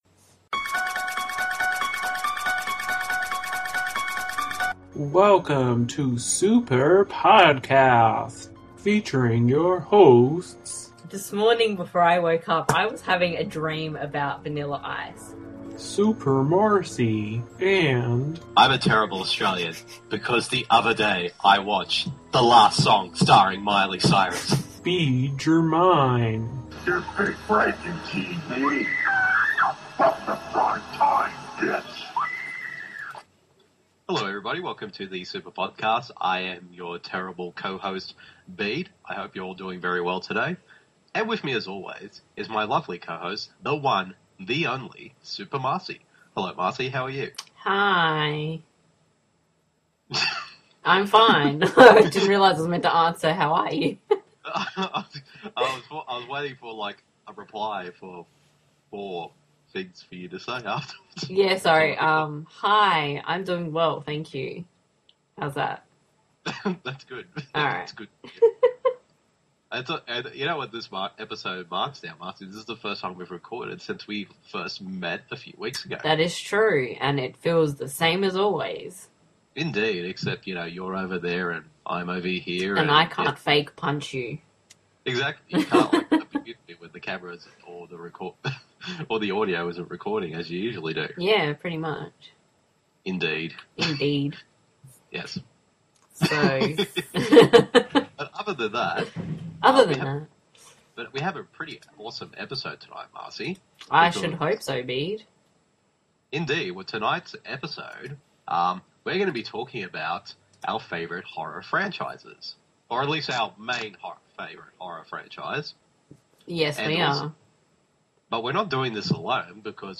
They are not alone, a brand new guest joins the Podcast!